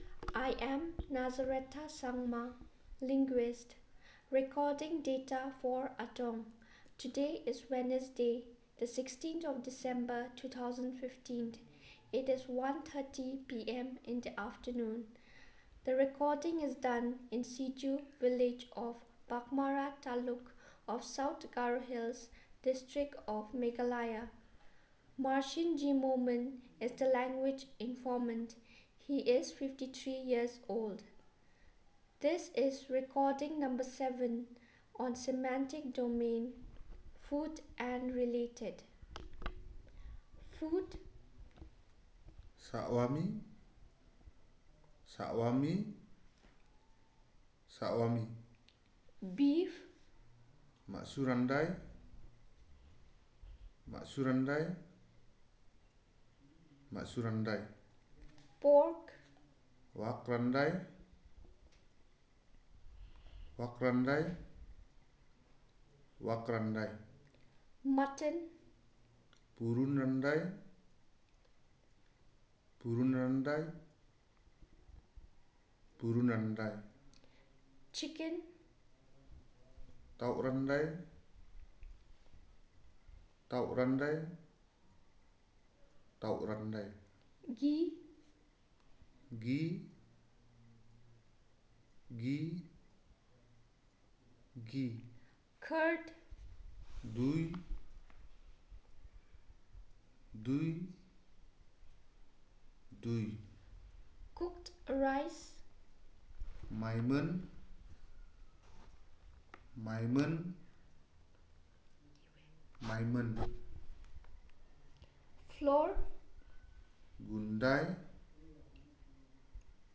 Elicitation of words about food and related